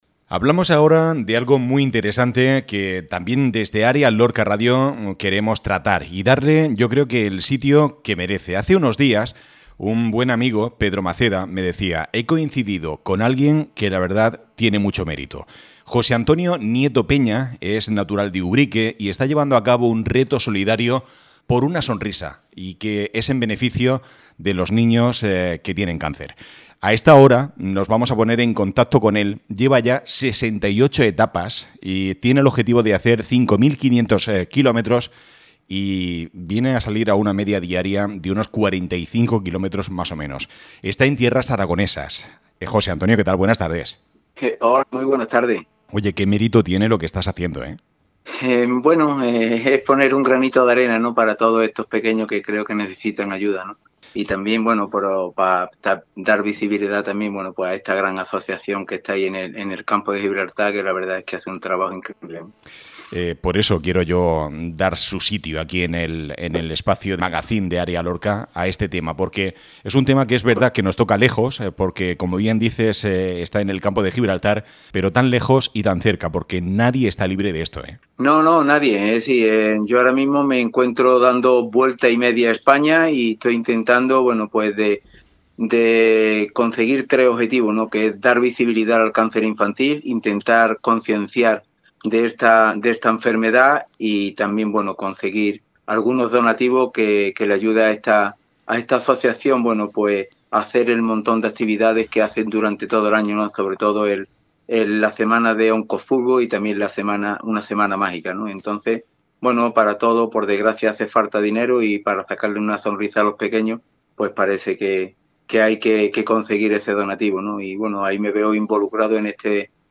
Escucha aquí la interesante entrevista que ha concedido este «héroe sin capa» a Área Lorca Radio. https